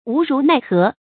無如奈何 注音： ㄨˊ ㄖㄨˊ ㄣㄞˋ ㄏㄜˊ 讀音讀法： 意思解釋： 無可奈何。